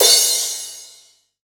Brush Crash.wav